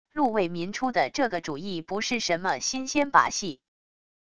陆为民出的这个主意不是什么新鲜把戏wav音频生成系统WAV Audio Player